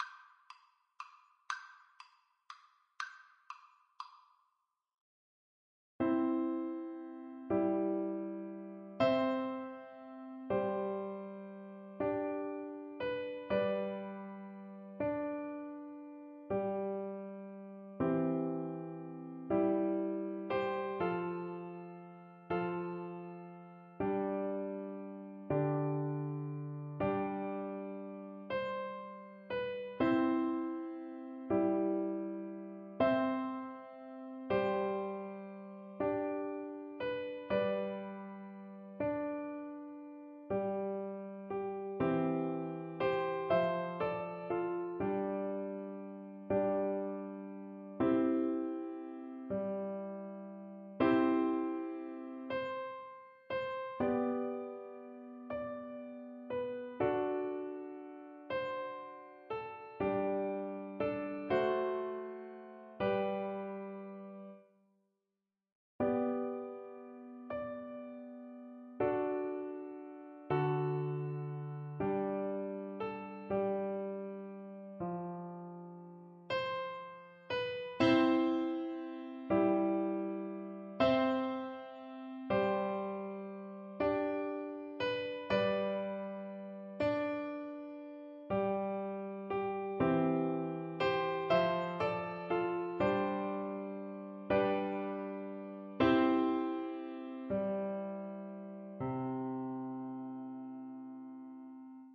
Flute version
6/8 (View more 6/8 Music)
G5-G6
Maestoso . = c. 60